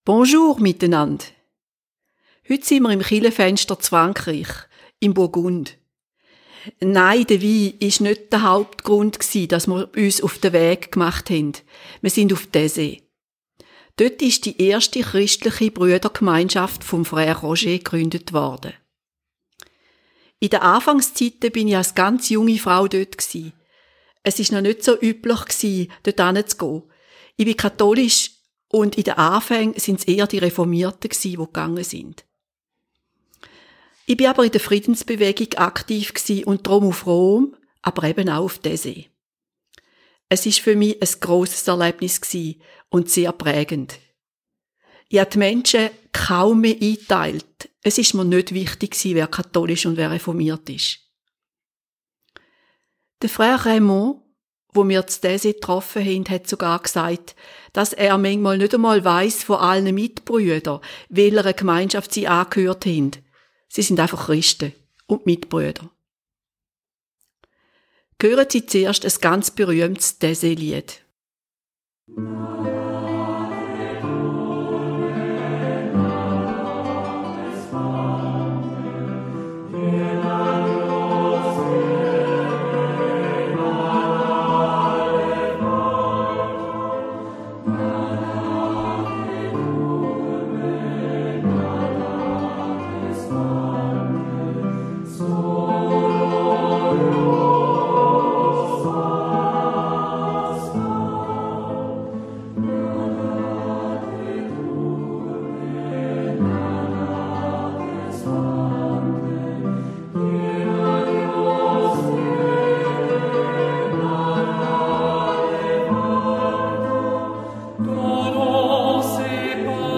Ein Abendgottesdienst mit Lichtern und einen Sonntagsgottesdienst in der Kirche der Communauté dürfen wir mitfeiern. Geschichten von früher ploppen bei vielen auf, und wir lassen das Heute auf uns wirken.Bei einem Gespräch mit einem Bruder aus Indonesien erfahren wir, was sie als Gemeinschaft bewegt und wie sie die Zukunft von Taizé sehen.